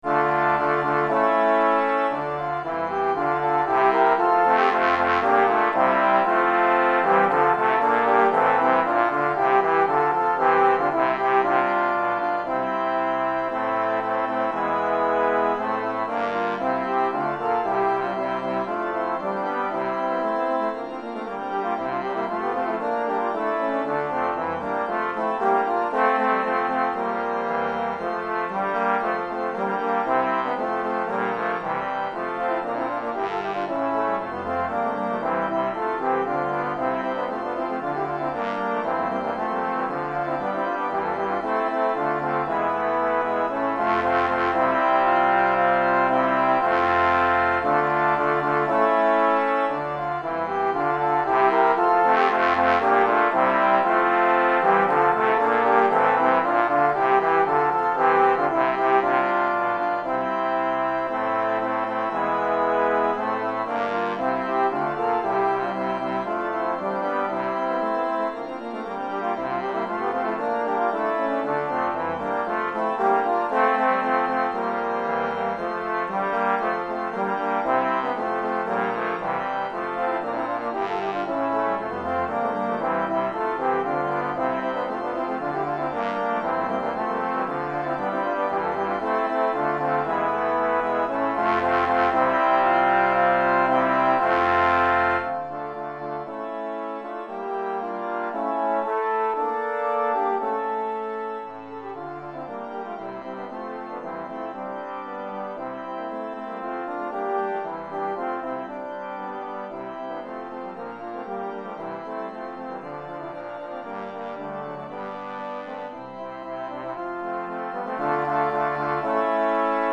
Répertoire pour Trombone - 3 Trombones, Trombone Basse et Orgue, en vente chez LMI - Suivi d'expédition, satisfait ou remboursé, catalogue de 300 000 partitions